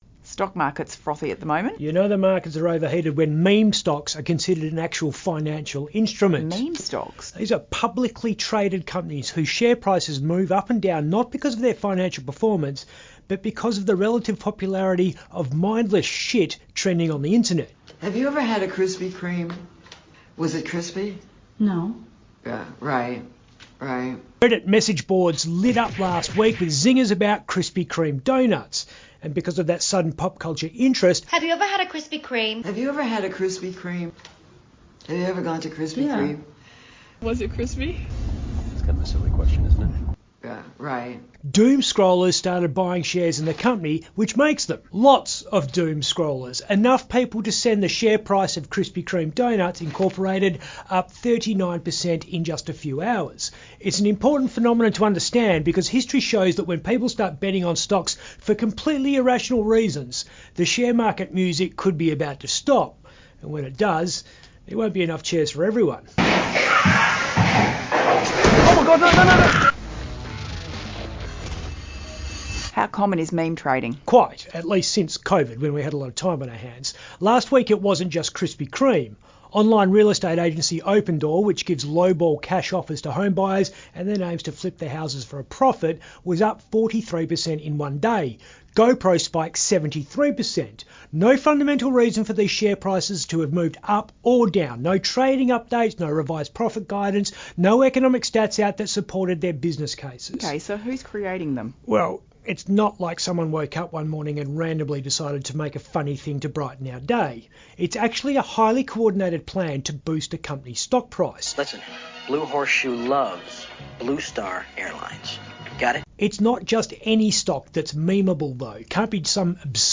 two-speakers-2.mp3